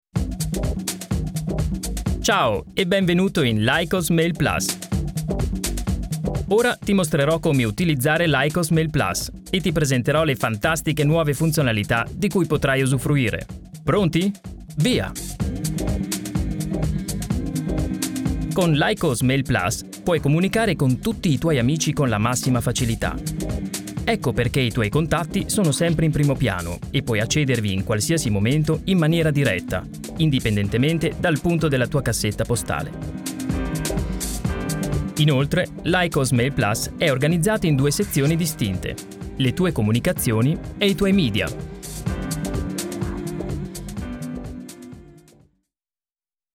smooth, enthusiastic and warm with his Italian mother tongue accent
Sprechprobe: Industrie (Muttersprache):
Italien voice over artist, his vocal range is perfectly suited for corporate narration, IVR and commercials in several other languages, including English, German and Spanish.